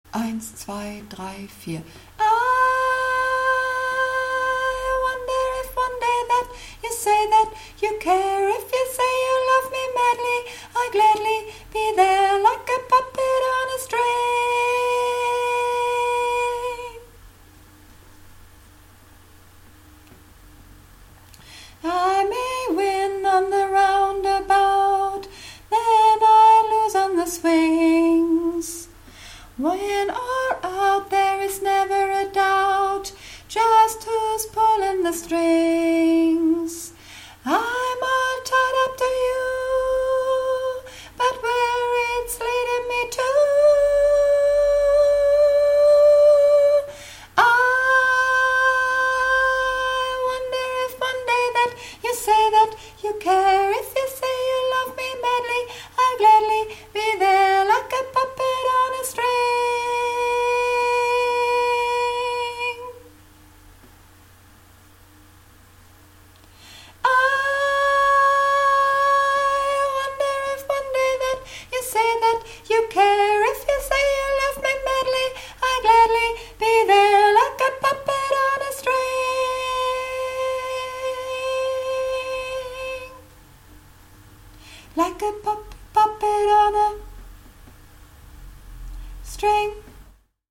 (Übungsaufnahmen)
Puppet On A String - Sopran